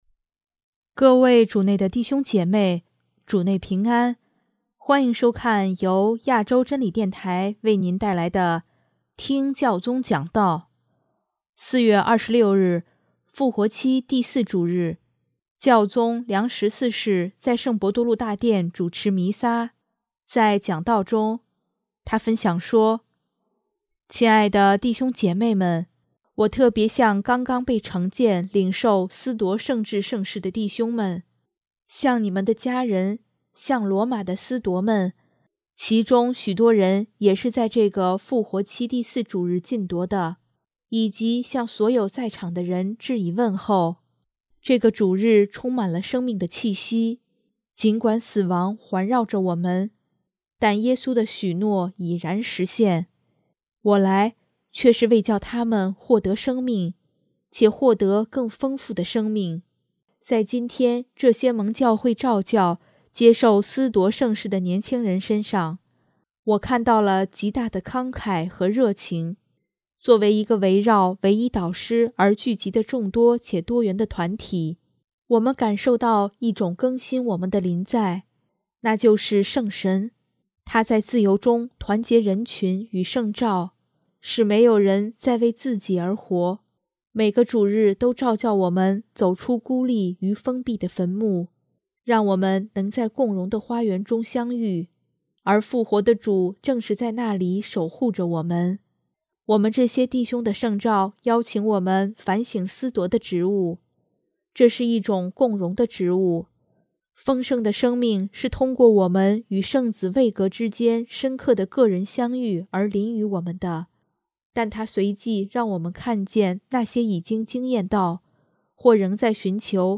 4月26日，复活期第四主日，教宗良十四世在圣伯多禄大殿主持弥撒，在讲道中，他分享说：